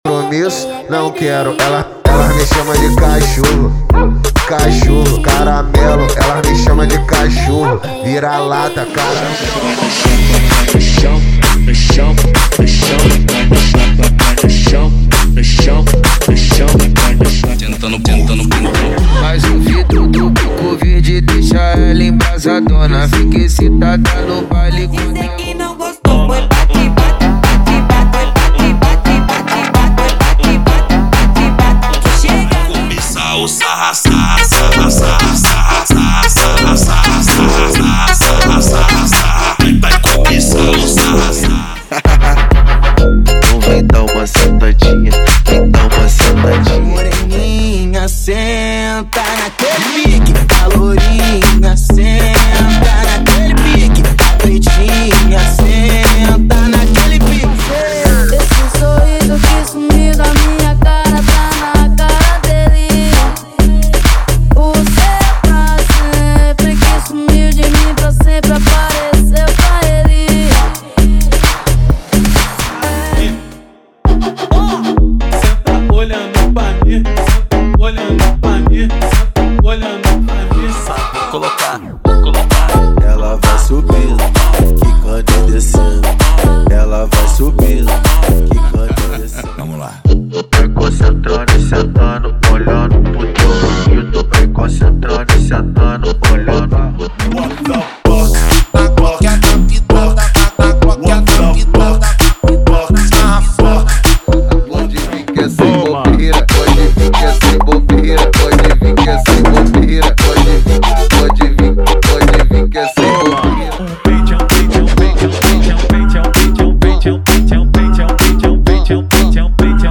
🔥 Eletro Funk Light
Versão Ligth Sem Palavrão
✔ Músicas sem vinhetas